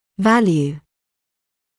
[‘væljuː][‘вэлйуː]значение, число, величина; ценность